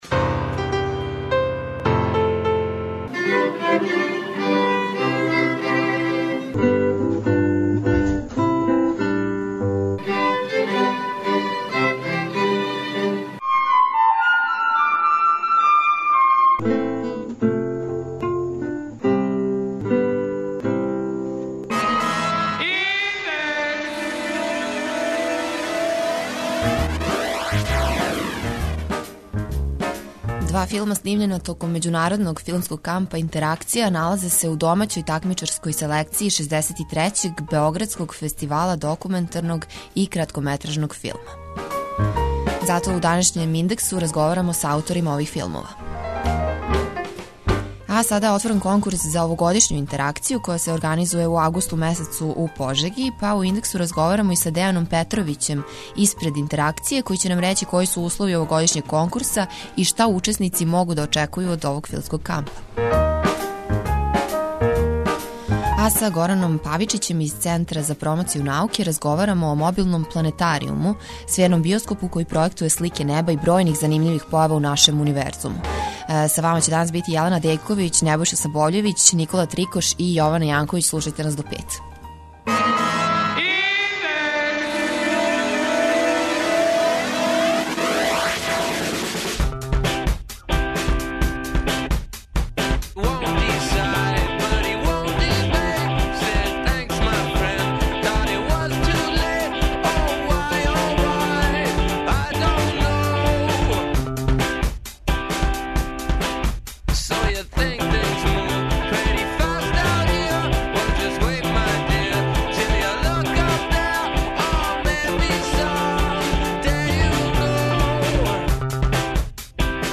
У данашњем Индексу разговарамо са организаторима Интеракције, међународног студентског филмског кампа.
преузми : 19.08 MB Индекс Autor: Београд 202 ''Индекс'' је динамична студентска емисија коју реализују најмлађи новинари Двестадвојке.